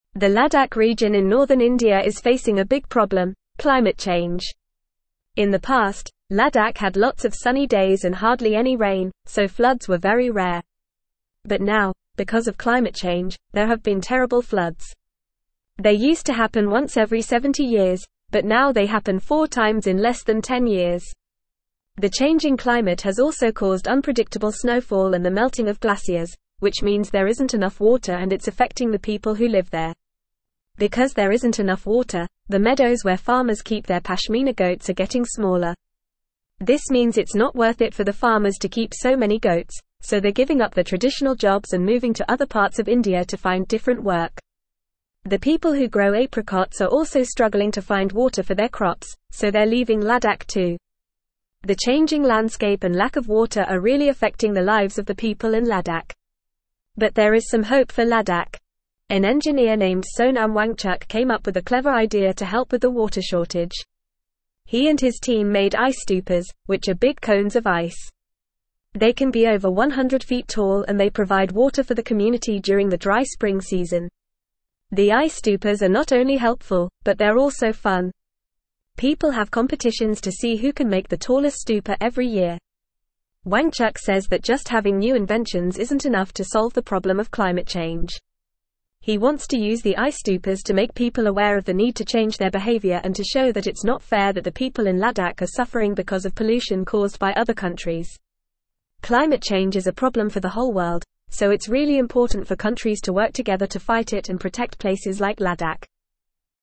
Fast
English-Newsroom-Upper-Intermediate-FAST-Reading-Ladakhi-People-Battle-Climate-Change-with-Ice-Stupas.mp3